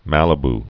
(mălĭ-b)